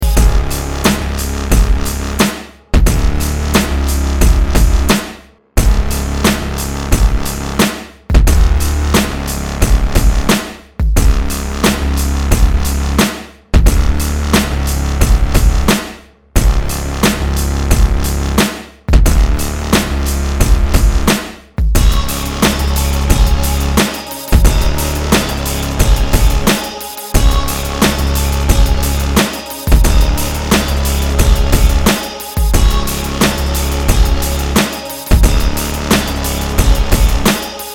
• Качество: 320, Stereo
жесткие
мощные басы
без слов
мрачные
Trip-Hop